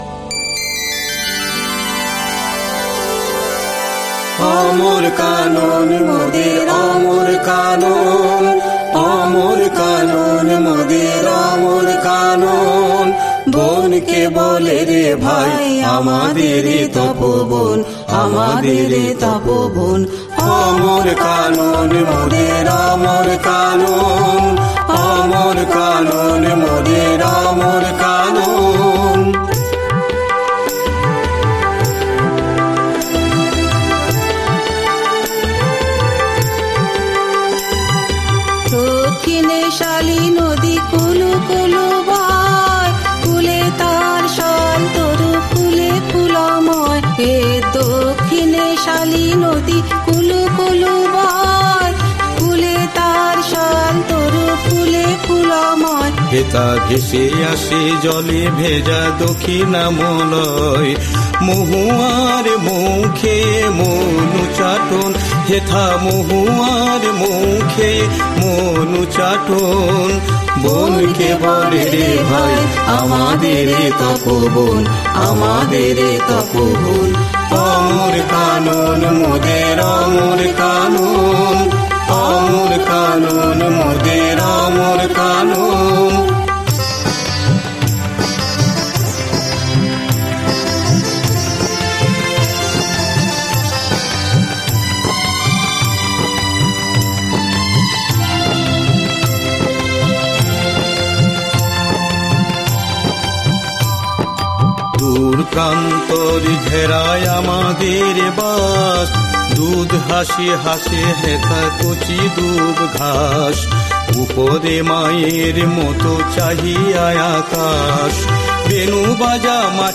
রাগ: বেহাগ-খাম্বাজ, তাল: কাওয়ালি